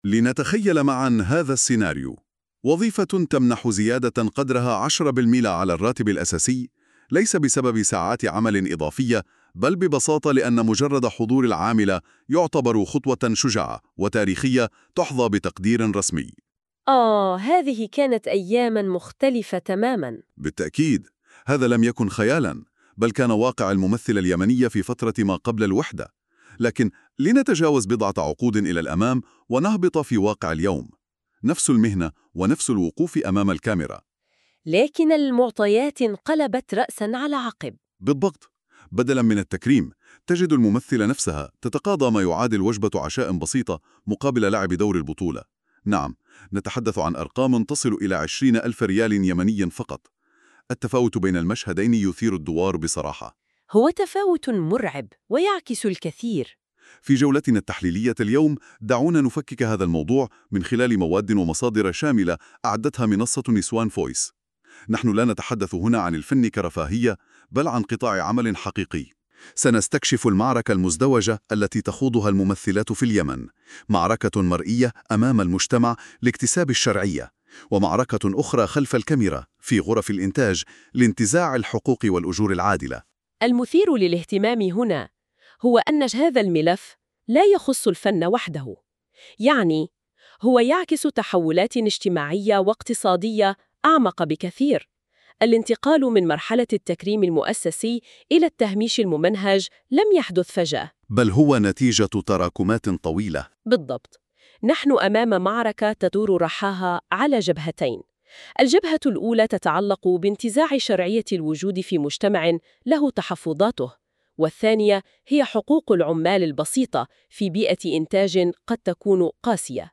ملخص صوتي قصير للمادة المكتوبة مولد بالذكاء الاصطناعي بواسطة Notebook KLM